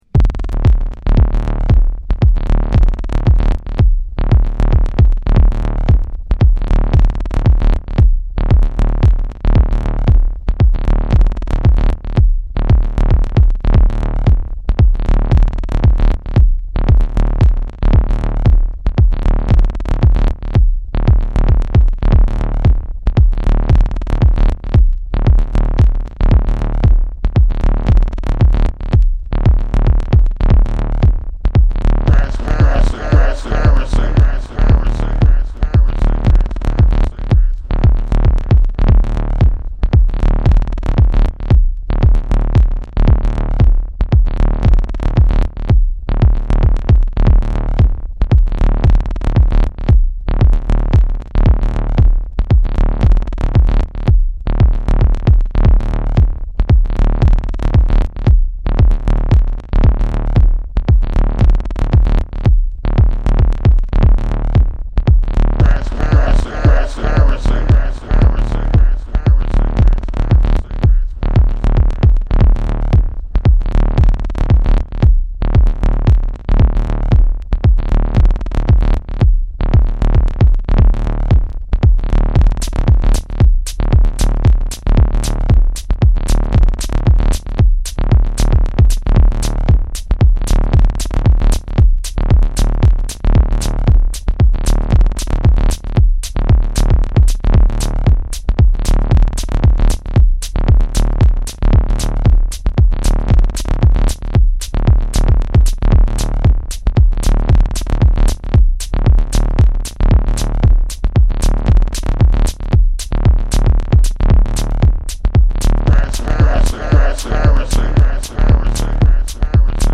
テクノハウス